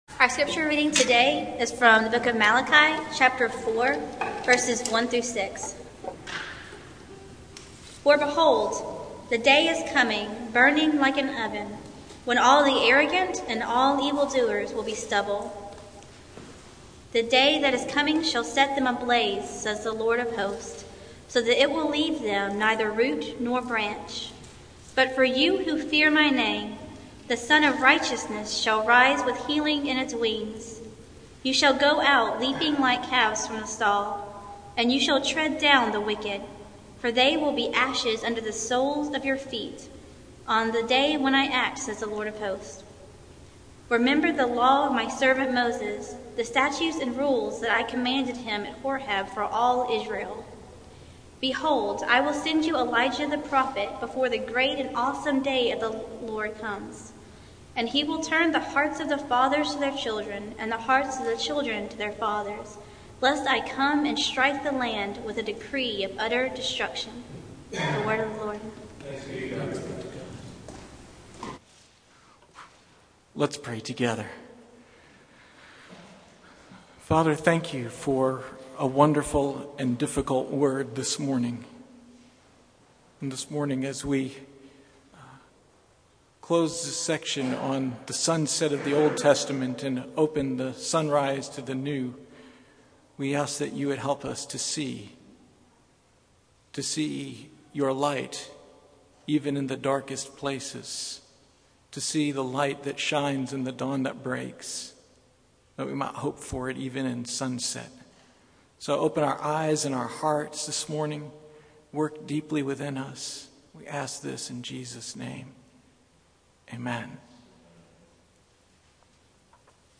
Passage: Malachi 4:1-4 Service Type: Sunday Morning